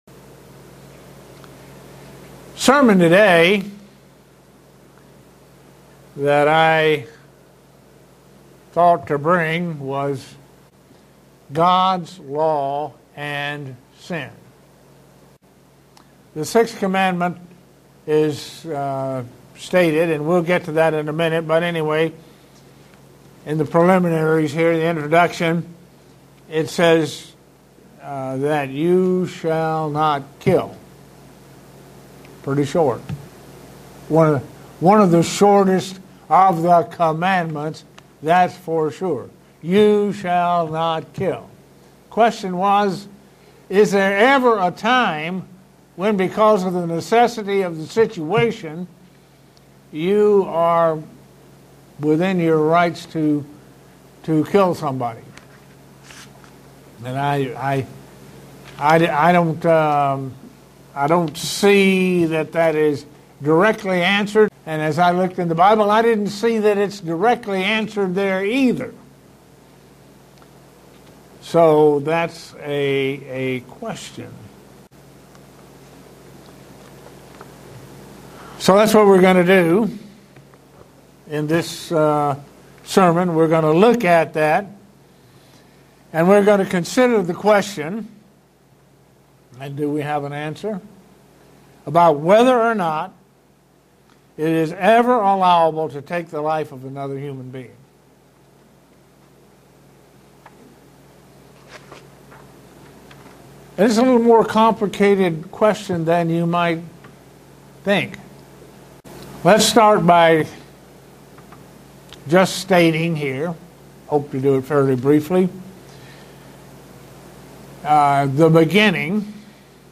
Given in Buffalo, NY
Print Is it ever allowed to take a human life, murder or kill. sermon Studying the bible?